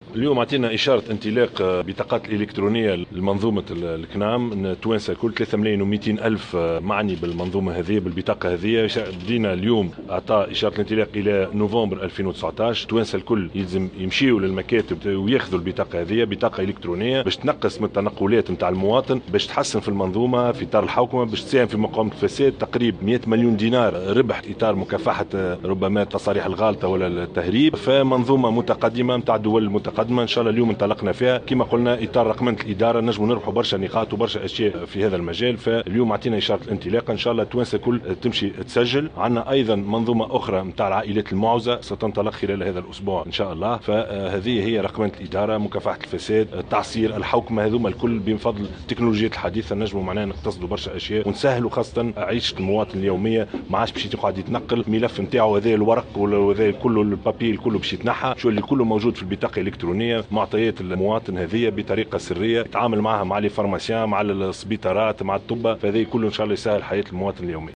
وأكد الشاهد، في تصريح لمراسل الجوهرة أف أم، أن اعتماد هذه البطاقة سيخفف على المنتفعين بها عبء التنقلات لتكوين الملف الطبي، وسيمكن من توفير مبلغ يقدر بـ100 مليون دينار عبر قضائها على التصاريح المفتعلة ومقاومتها لتهريب الأدوية وسرقتها.